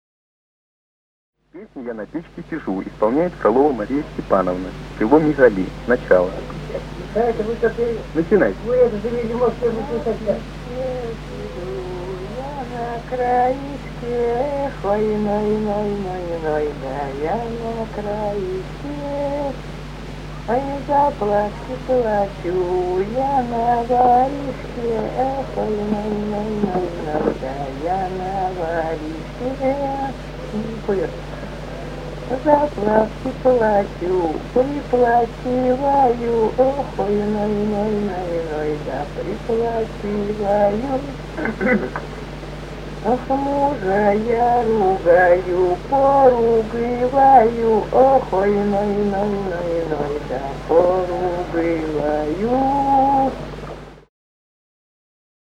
Русские народные песни Владимирской области 37. На печке сижу, я на краешке (плясовая) с. Михали Суздальского района Владимирской области.